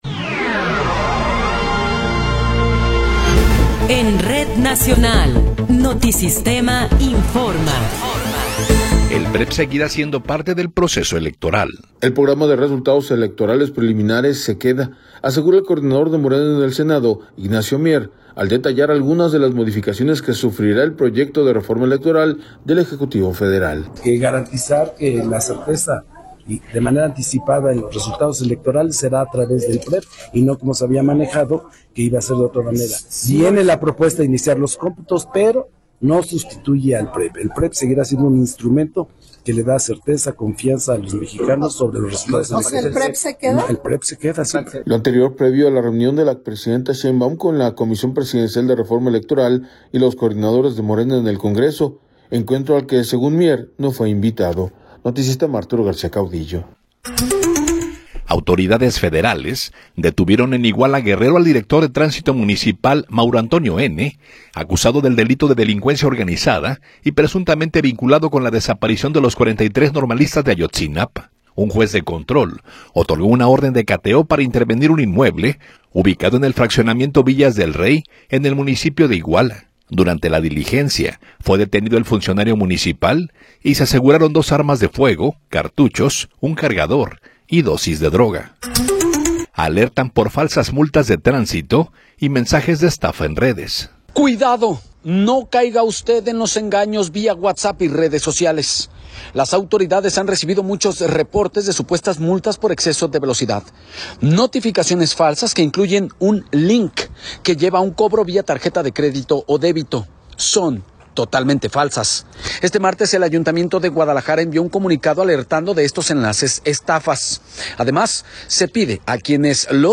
Noticiero 19 hrs. – 3 de Marzo de 2026
Resumen informativo Notisistema, la mejor y más completa información cada hora en la hora.